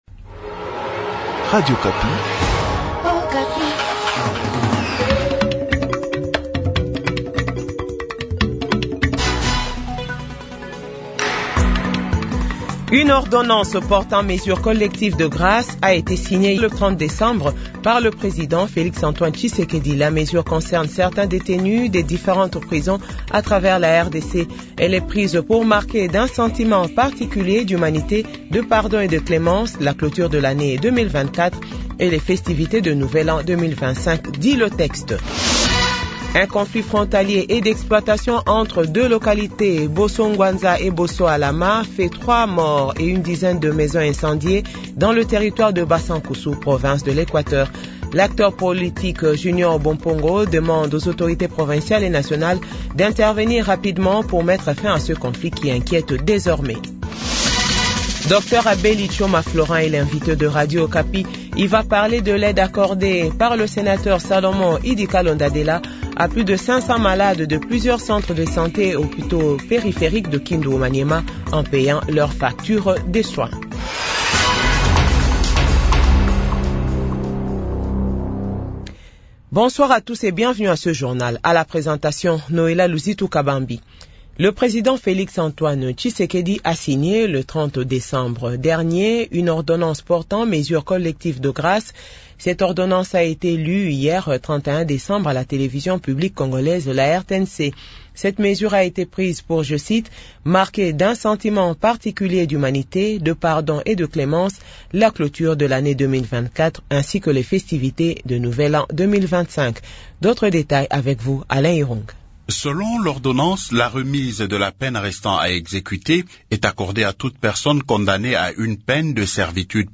JOURNAL FRANCAIS DE 18H00